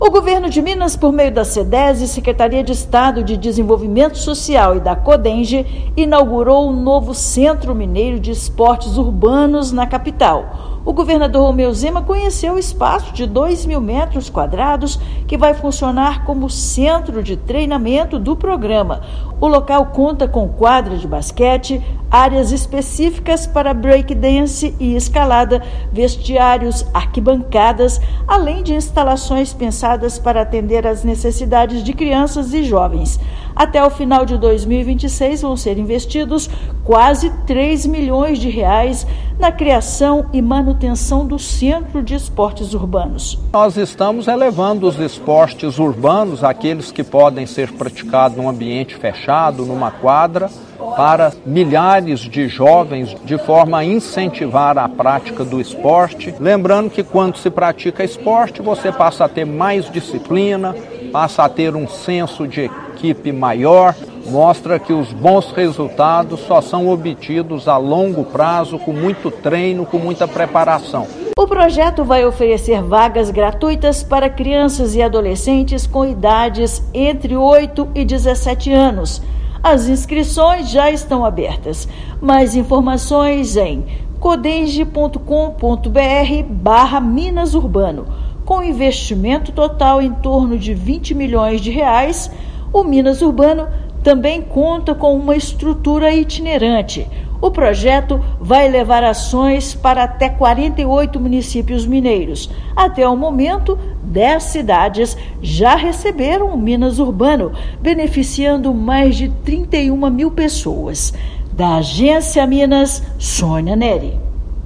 Espaço vai funcionar como centro de treinamento do programa Minas Urbano e deve atender, gratuitamente, 1,6 mil crianças e adolescentes até 2026. Ouça matéria de rádio.